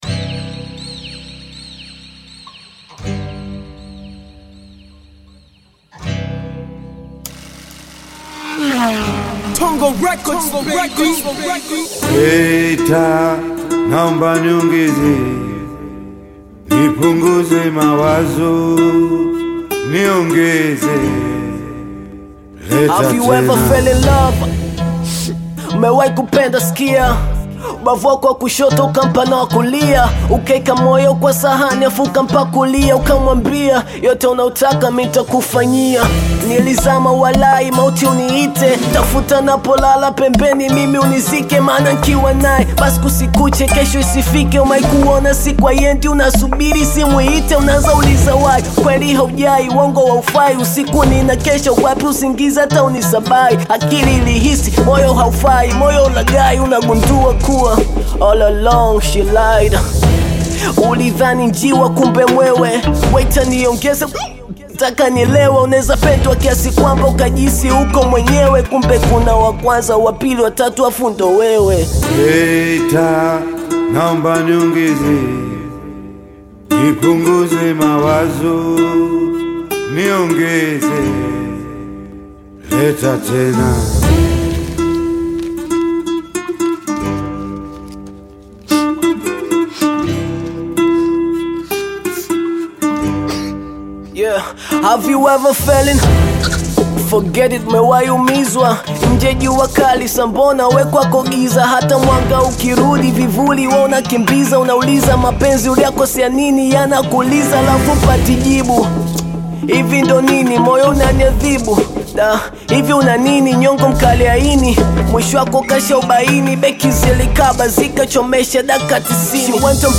Hip-hop genre based artist
African Music